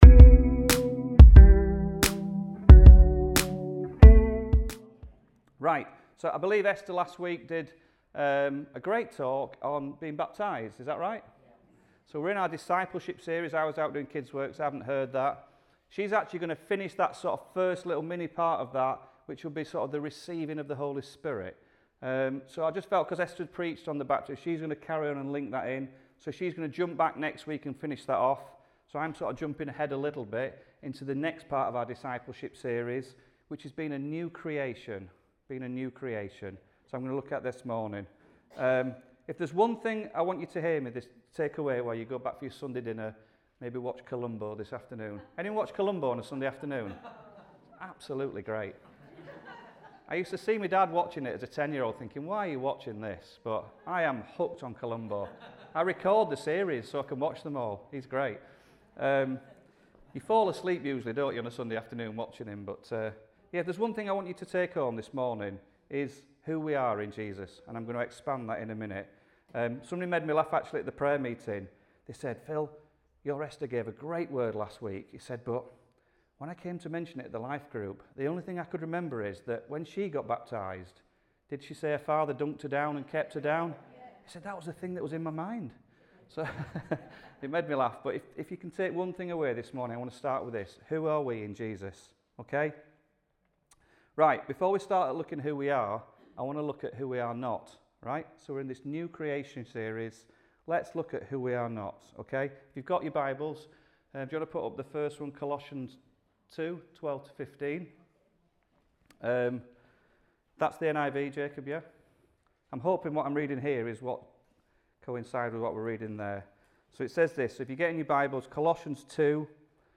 Rediscover Church Newton Abbot | Sunday Messages A Discipleship Series - Part 3 | Who Am I?